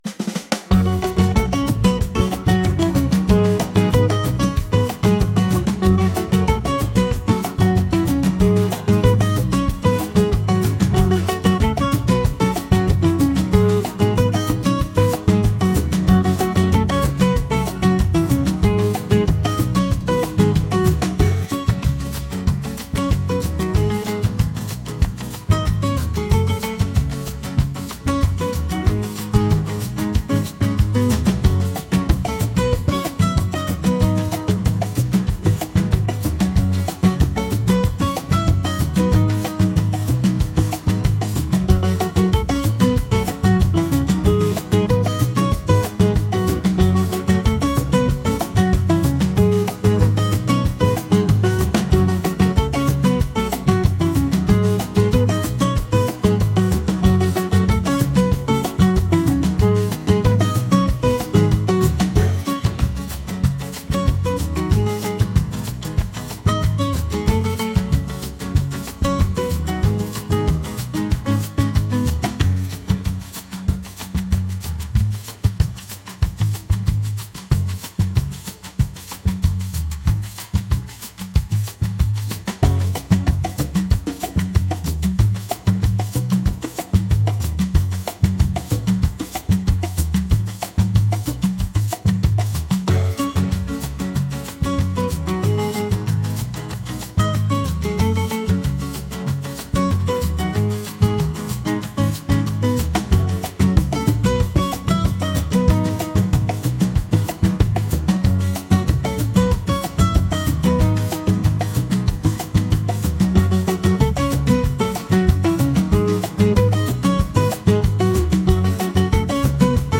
latin | upbeat | energetic